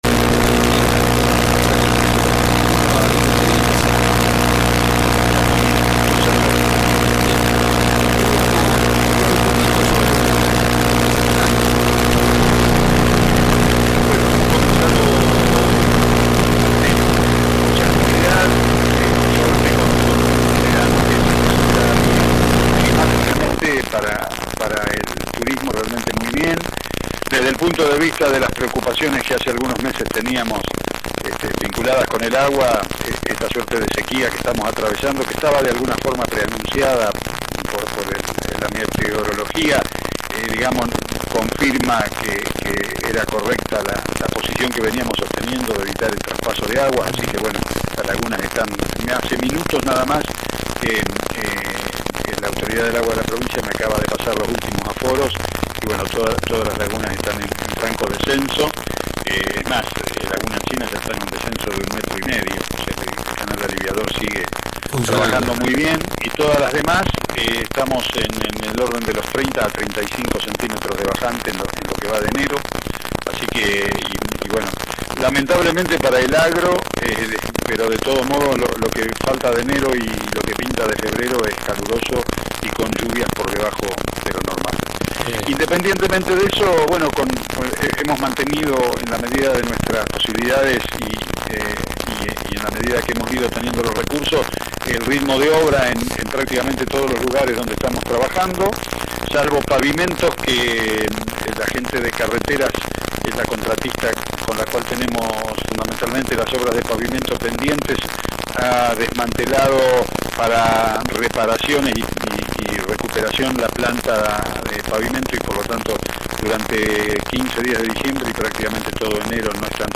Entrevista exclusiva al Intendente de Adolfo Alsina David Hirtz